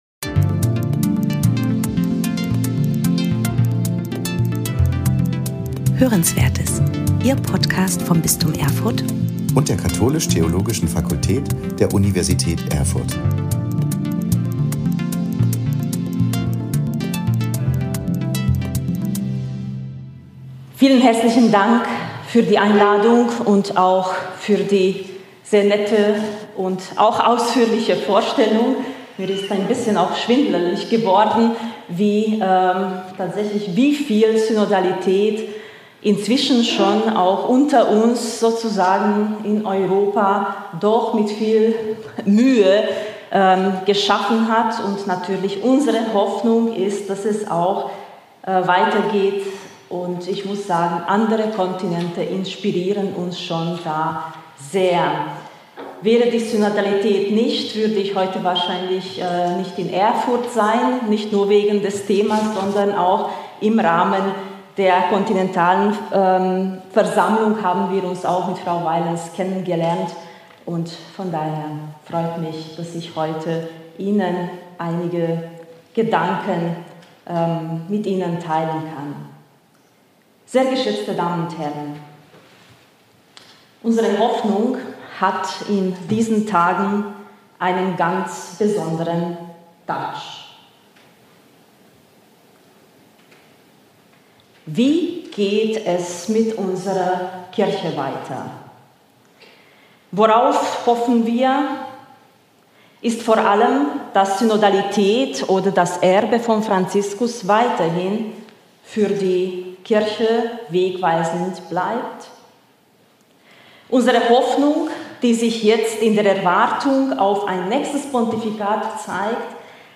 Spannende Vorträge und Interviews aus dem Bistum Erfurt, aufgenommen bei Veranstaltungen des Katholischen Forums, der katholisch-theologischen Fakultät Erfurt sowie Fortbildungen im Bistum Erfurt.